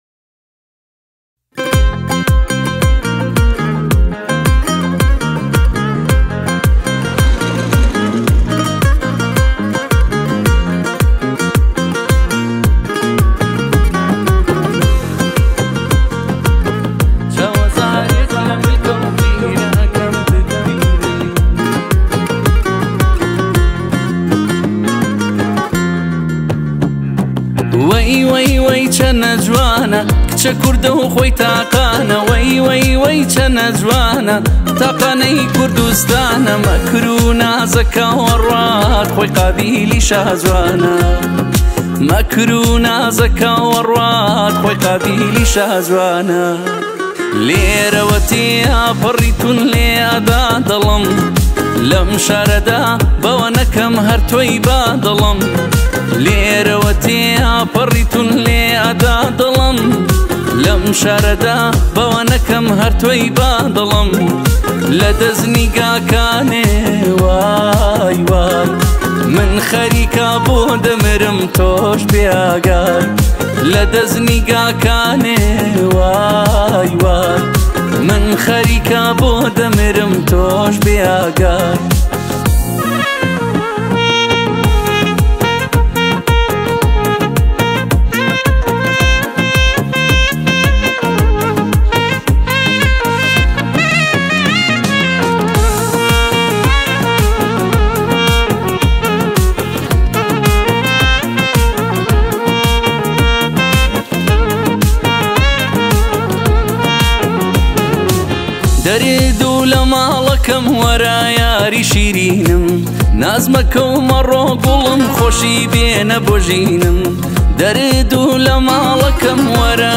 آهنگ کردی پاپ